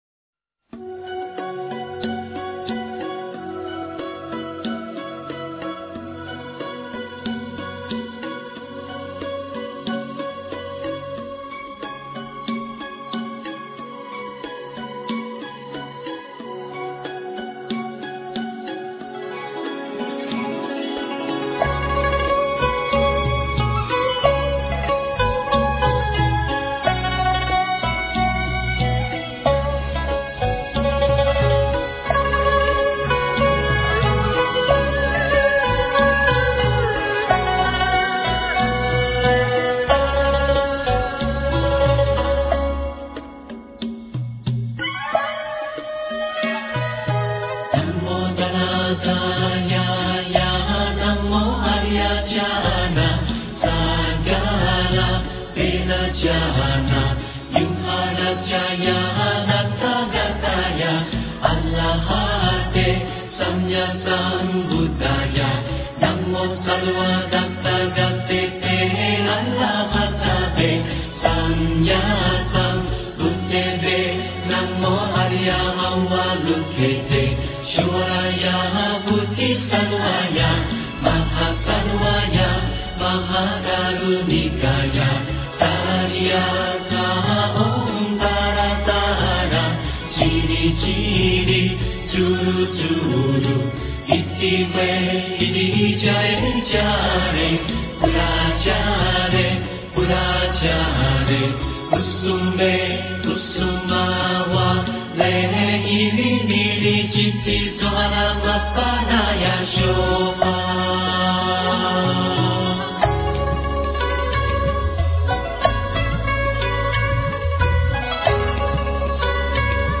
大悲咒 诵经 大悲咒--未知 点我： 标签: 佛音 诵经 佛教音乐 返回列表 上一篇： 大悲咒 下一篇： 大悲咒 相关文章 大悲咒--圆光佛学院女众 大悲咒--圆光佛学院女众...